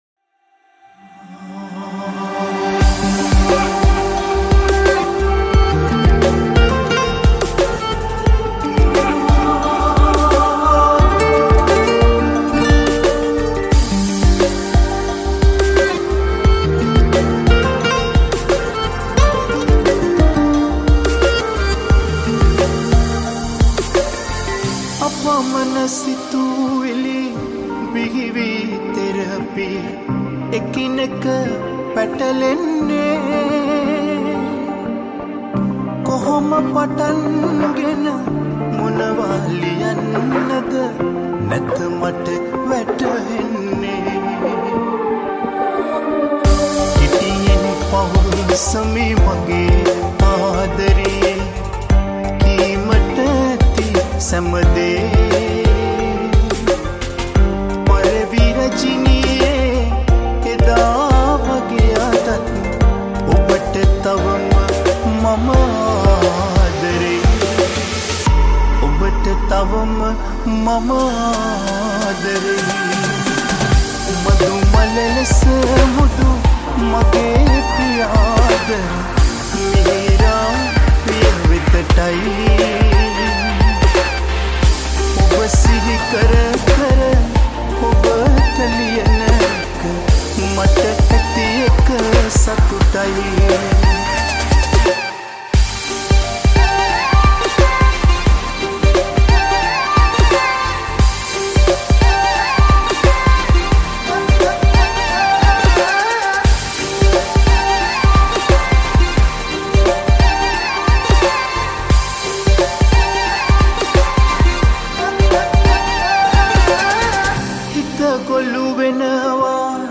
High quality Sri Lankan remix MP3 (3.6).